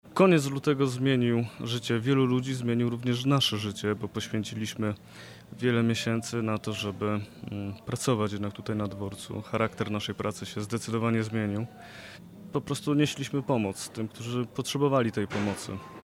Nadajemy program prosto z Dworca Głównego PKP we Wrocławiu, z kawiarni Stacja Dialog.